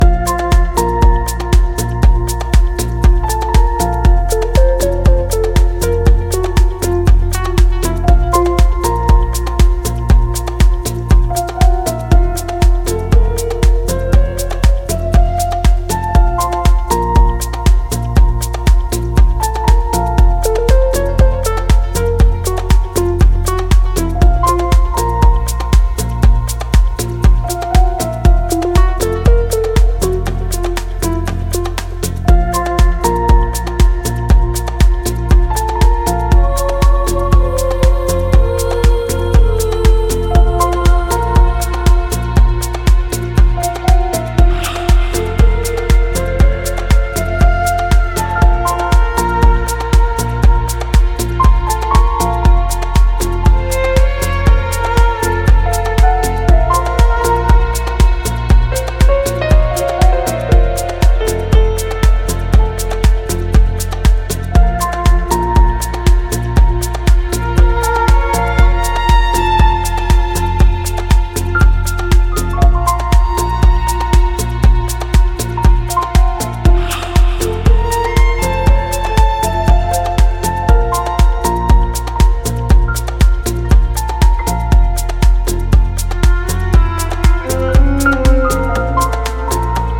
ジャンル(スタイル) PROGRESSIVE HOUSE / DEEP HOUSE